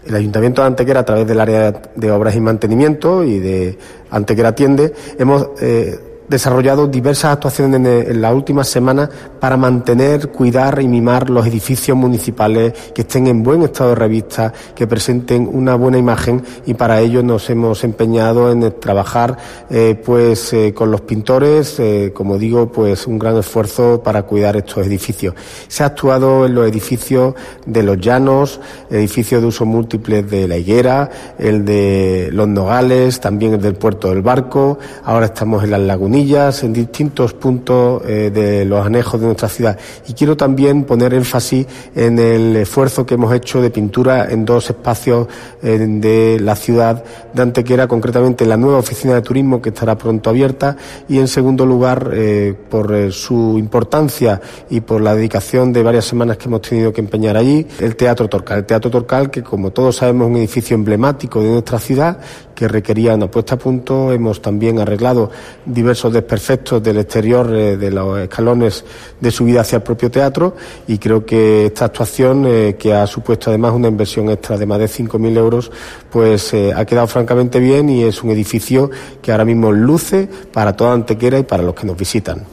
Cortes de voz J.R. Carmona 564.64 kb Formato: mp3